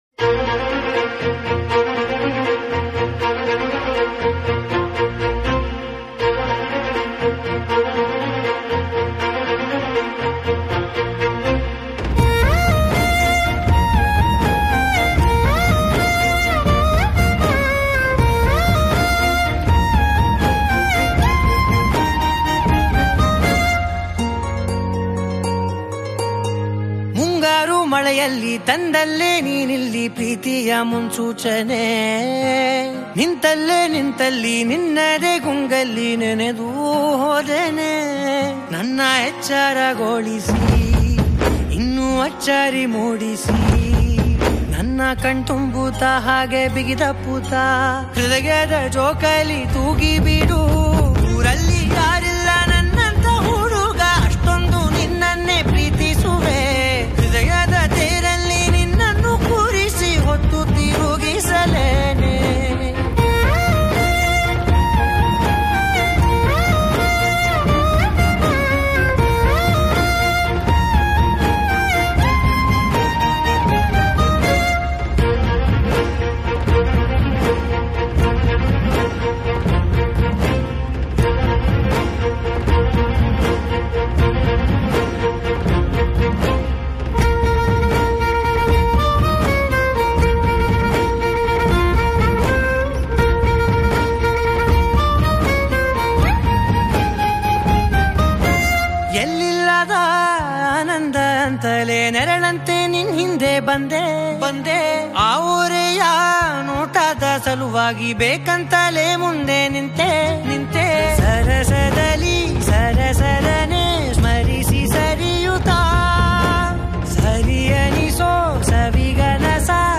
Kannada Songs